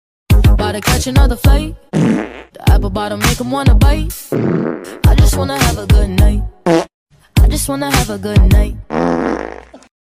Funny Fart Sound Effect 5 Sound Effects Free Download